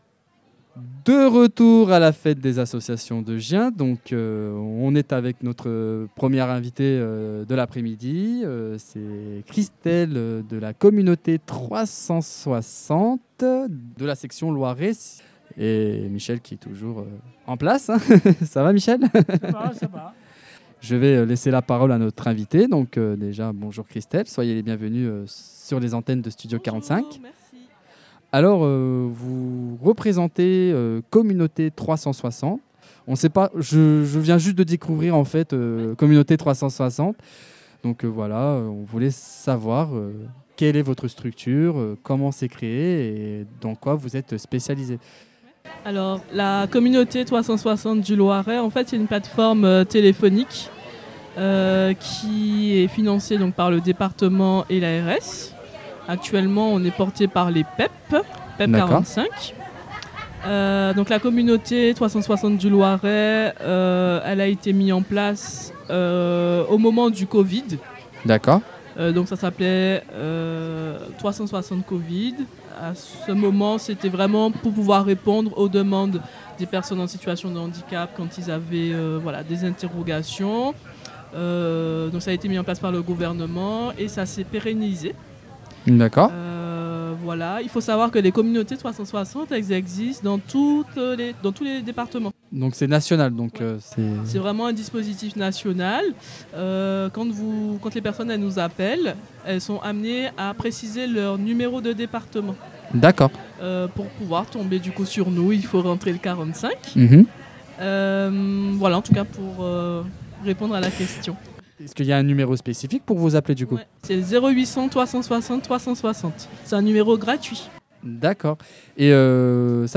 Fête des associations de Gien 2025 - Communauté 360